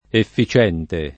effi©$nte] (meno bene efficente [id.]) agg. — grafia -cien- anche nel superl. -issimo e nei der. -ismo, -ista, -istico, nonostante la posiz. atona, oltreché nell’astratto efficienza [effi©$nZa] (meno bene efficenza [id.])